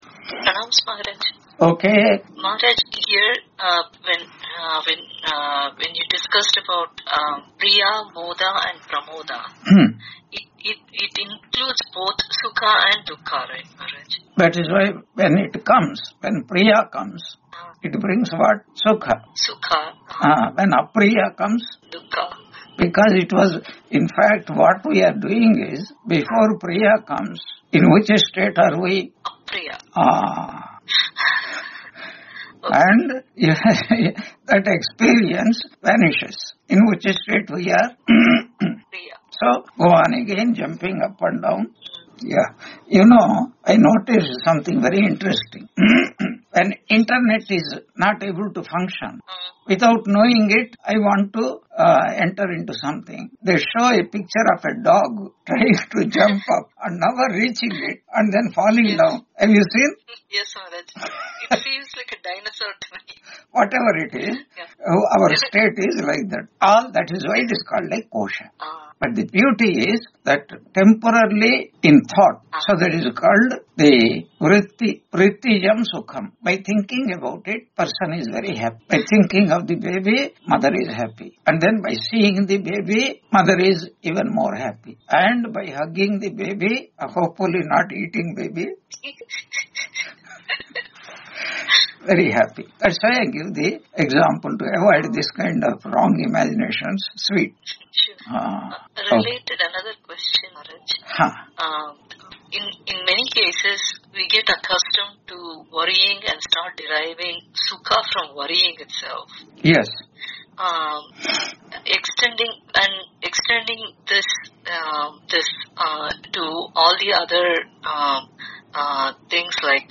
Taittiriya Upanishad Lecture 77 Ch2 5.3 on 05 November 2025 Q&A - Wiki Vedanta